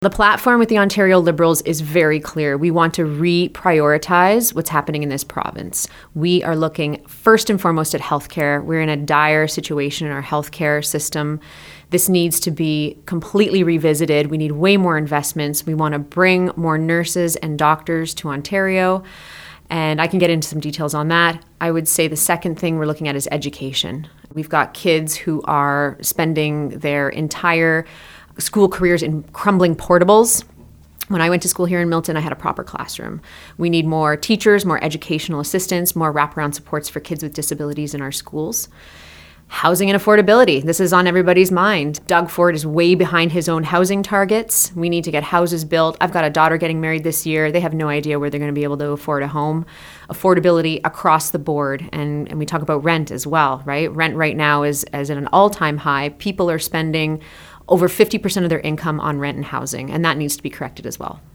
She chose to join us in-person at our Milton studios.
Here’s our interview: